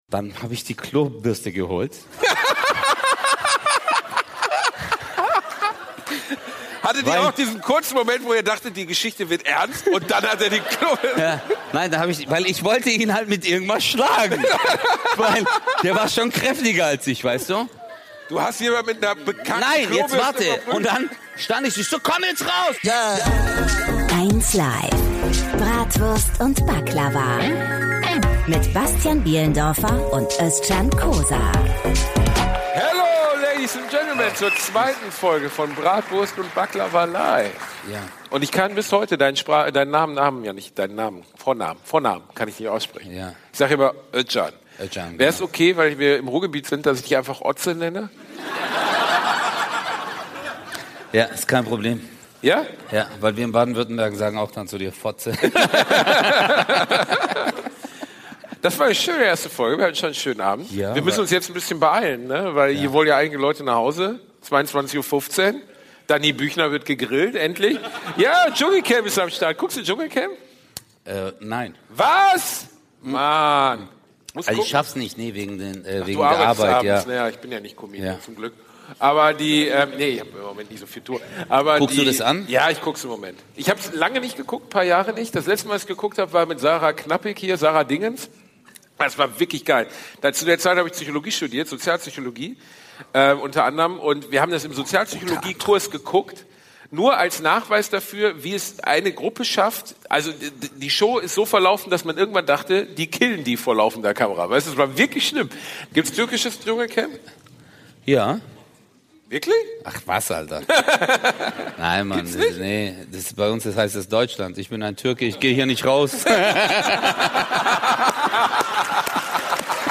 #19 Auge des Grauens - live beim 1LIVE Podcastfestival (Teil 2) ~ Bratwurst und Baklava - mit Özcan Cosar und Bastian Bielendorfer Podcast